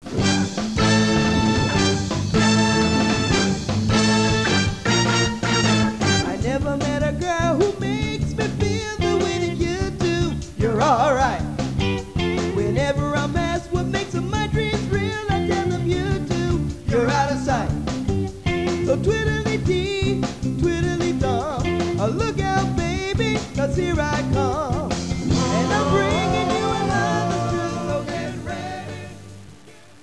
This CD was recorded during the winter/blizzard of '96'.
Lead Vocal
Tenor Sax Solo